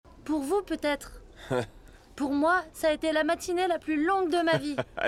IMDA - Voix enfant - Christmas Break in extrait 1